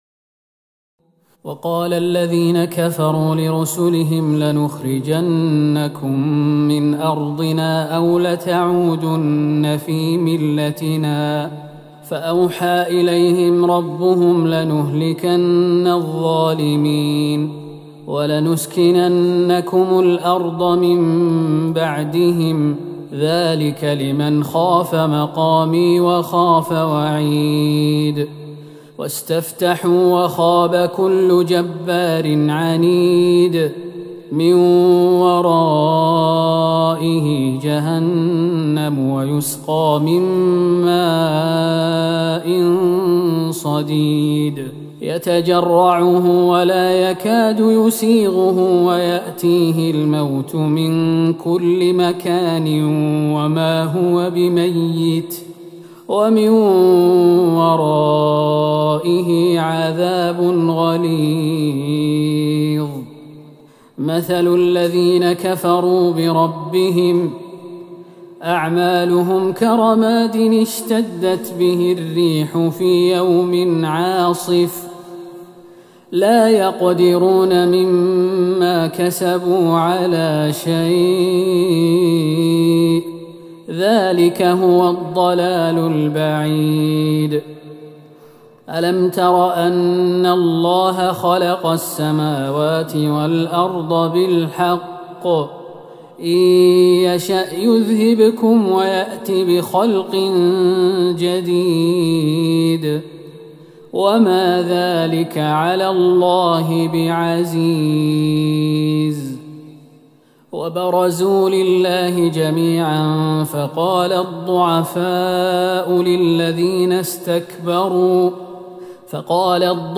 ليلة ١٨ رمضان ١٤٤١هـ من سورة إبراهيم { ١٣-٥٢ } والحجر { ١-٧٩ } > تراويح الحرم النبوي عام 1441 🕌 > التراويح - تلاوات الحرمين